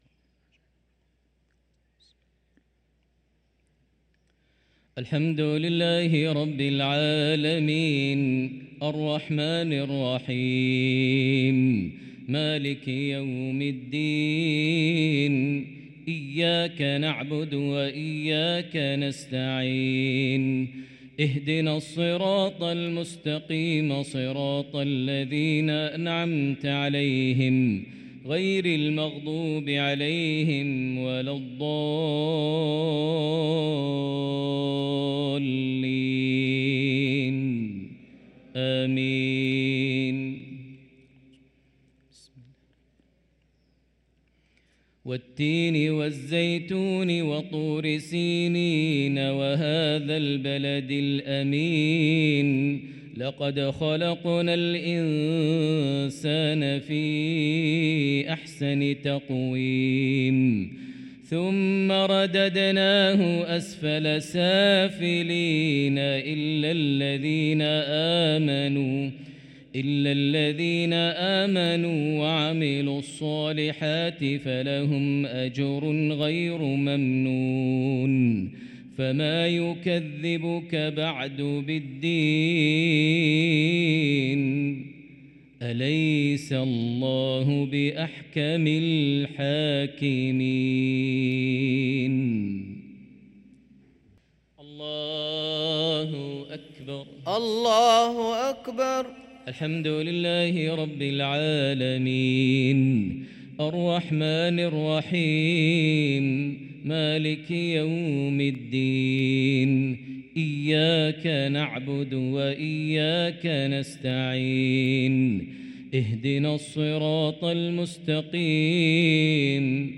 صلاة المغرب للقارئ ماهر المعيقلي 19 ربيع الأول 1445 هـ
تِلَاوَات الْحَرَمَيْن .